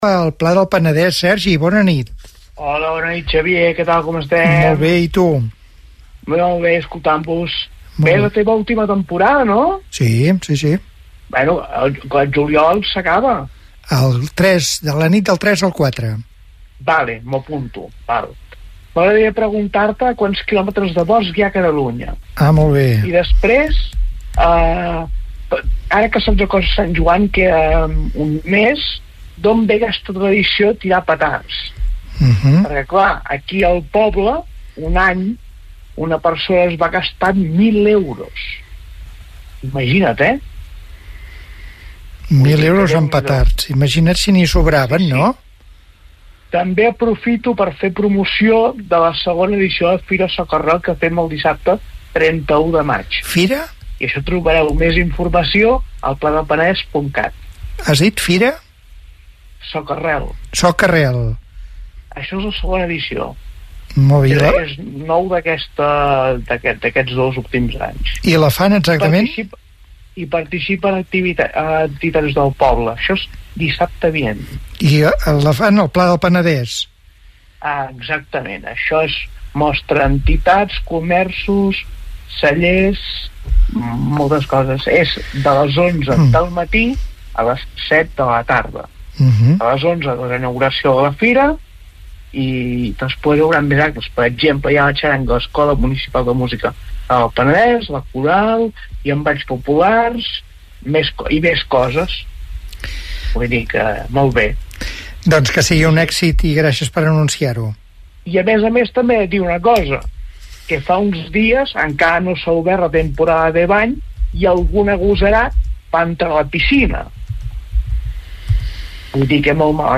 Trucada telefònica per fer un parell de preguntes i anuncair la Fira Socarrel a El Pla del Penedès